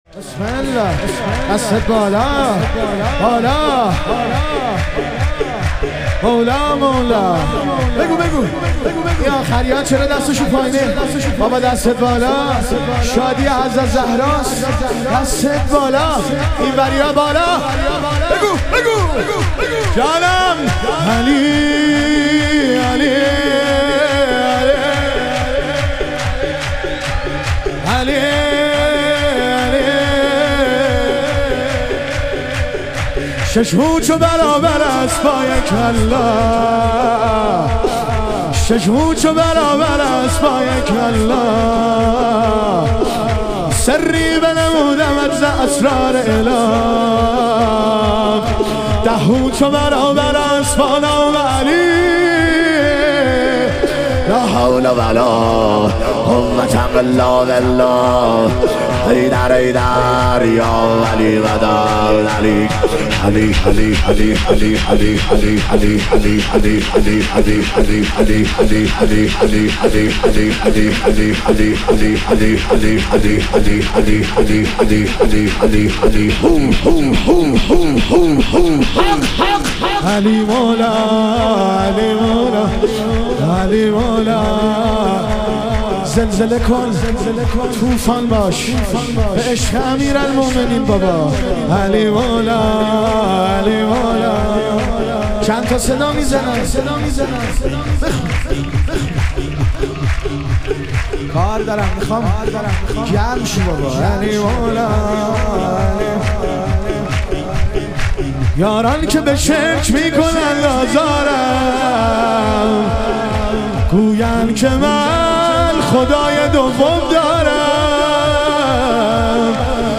ظهور وجود مقدس رسول اکرم و امام صادق علیهم السلام - شور